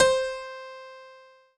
PIANO5-09.wav